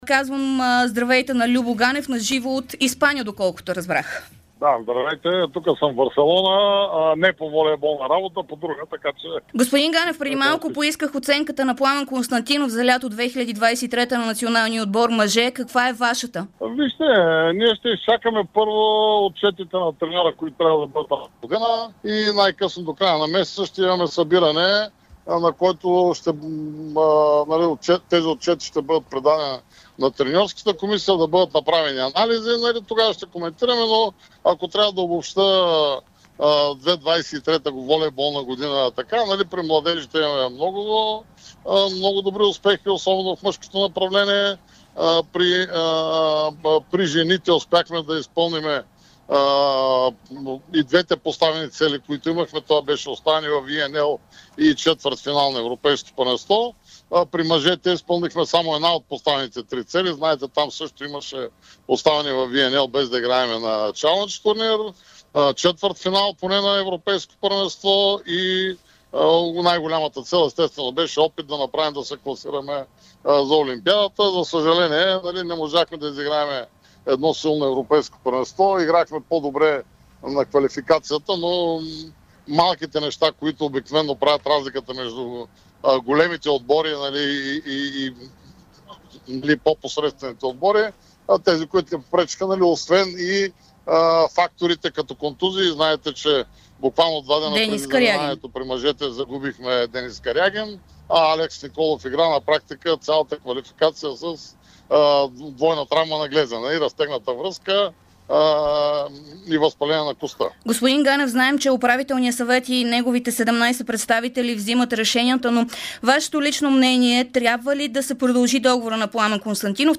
Президентът на Българската федерация по волейбол Любомир Ганев говори специално пред Дарик радио и dsport от Испания след края на мачовете на мъжкия ни национален отбор през лятото, в което страната ни игра мачове от Лигата на нациите, Европейското първенство и Олимпийската квалификация за място на Игрите в Париж през 2024.